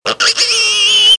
pig